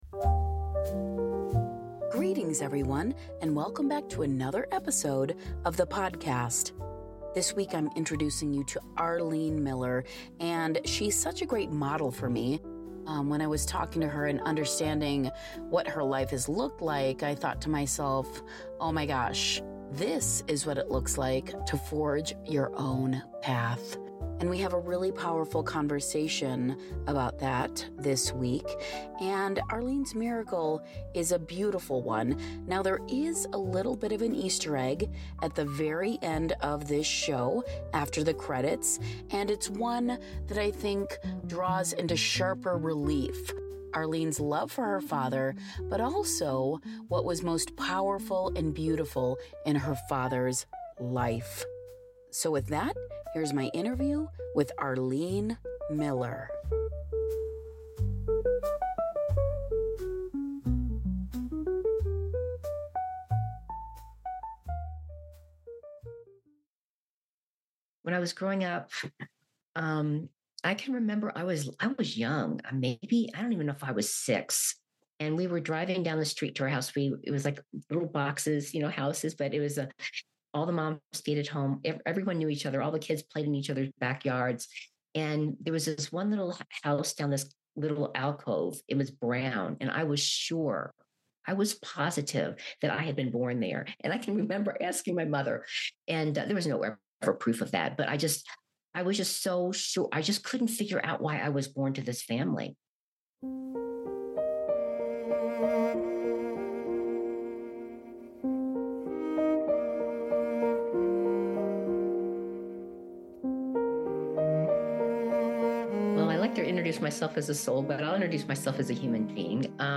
245: Interview: